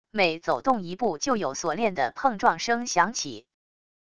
每走动一步就有锁链的碰撞声响起wav音频